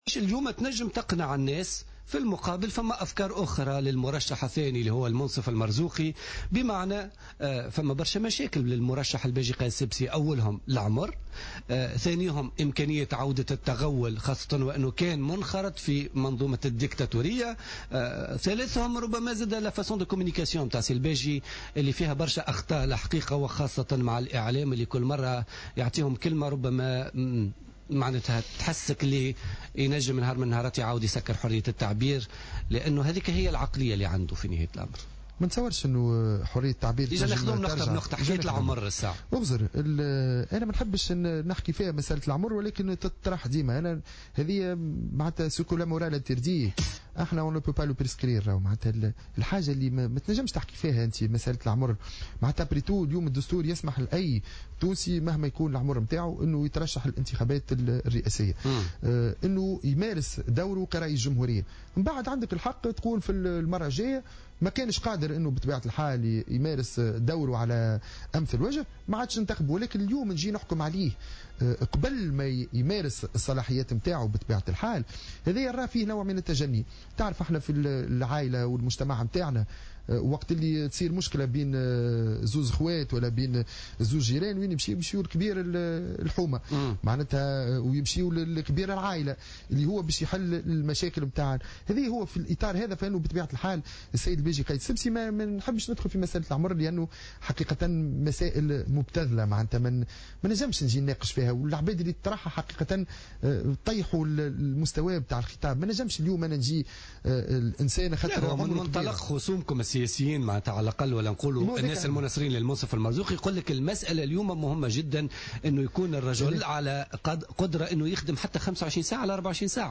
قال النائب السابق بالمجلس التأسيسي محمود البارودي ضيف برنامج بوليتيكا اليوم الثلاثاء 9 ديسمبر 2014 إن الحكم على الباجي قايد السبسي قبل ممارسة صلاحياته كرئيس للجمهورية من منطلق السن ومبدأ التغول فيه نوع من التجني .